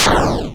missilehit.wav